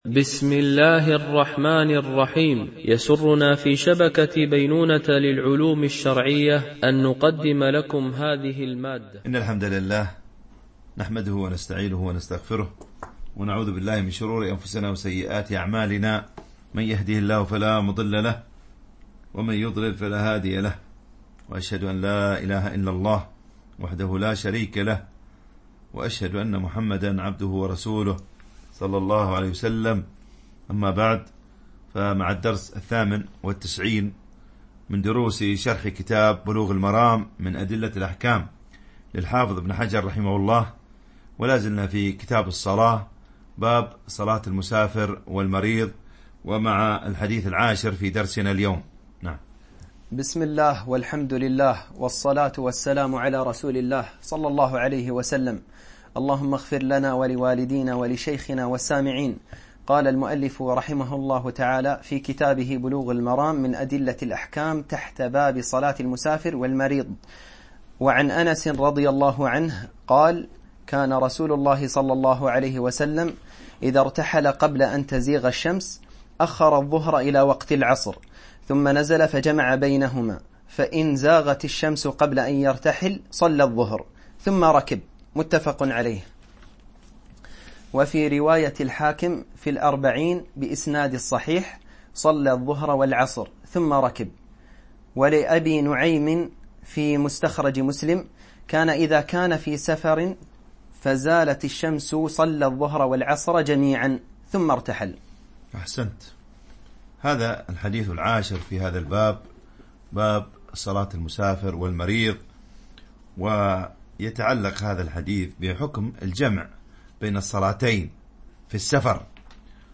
MP3 Mono 22kHz 32Kbps (CBR)